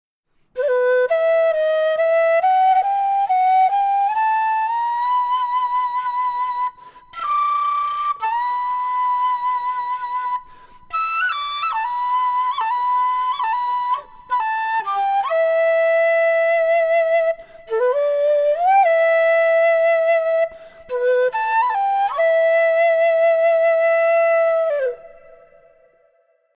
Кена (Quena, Jacaranda, Ramos, G) Перу
Материал: жакаранда.